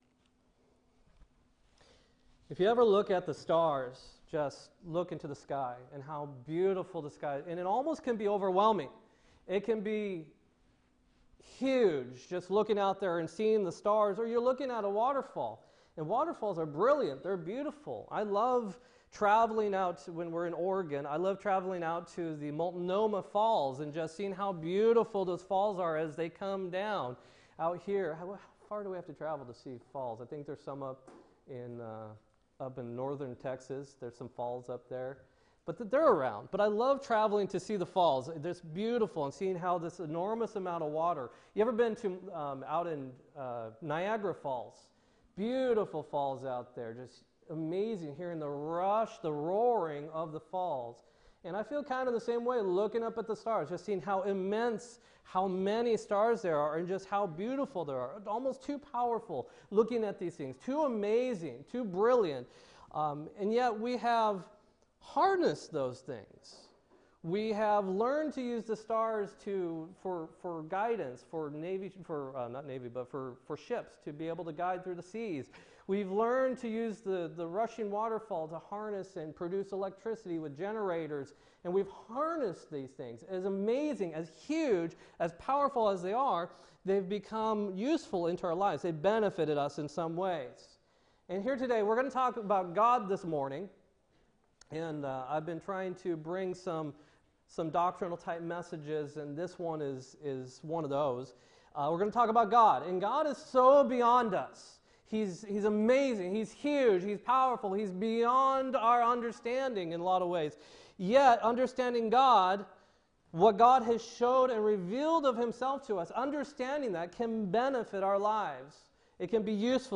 2-4-17 sermon